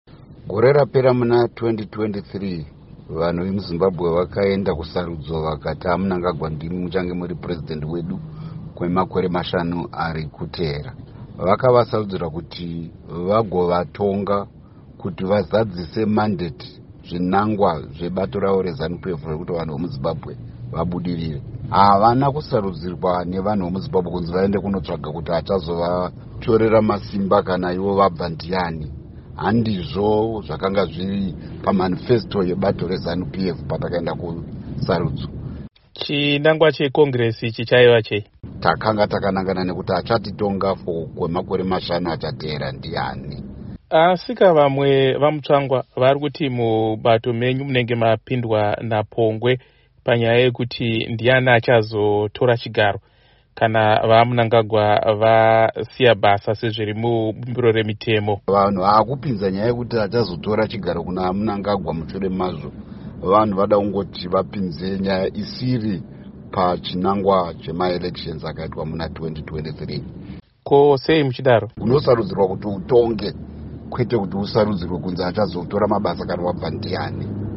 Hurukuro naVaChris Mutsvangwa